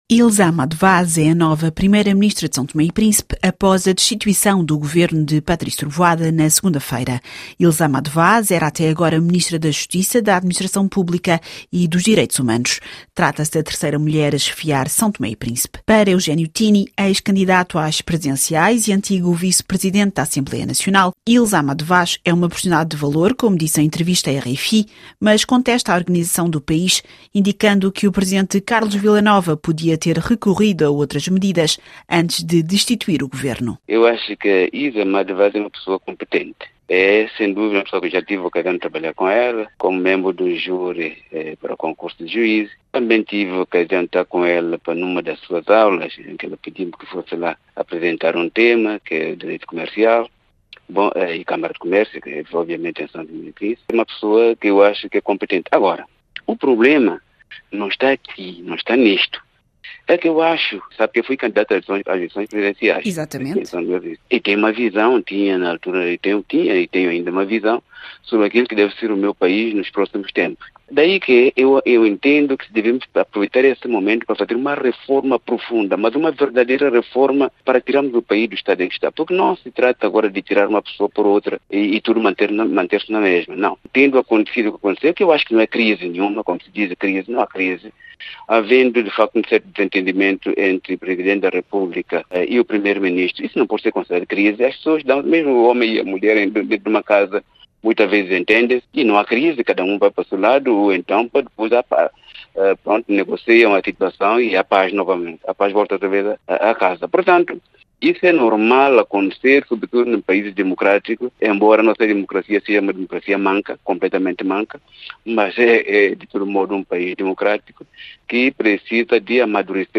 O antigo candidato às presidenciais Eugénio Tiny disse em entrevista à RFI que o Presidente Carlos Vila Nova tinha outros mecanismos antes de demitir Patrice Trovoada, como convocar a Assembleia Nacional.